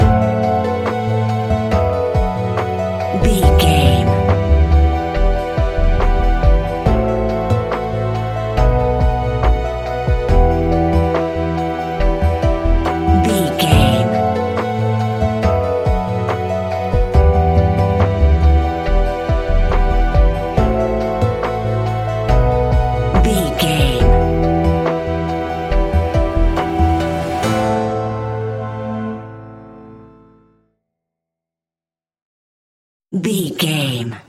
Ionian/Major
laid back
sparse
new age
chilled electronica
ambient
atmospheric
instrumentals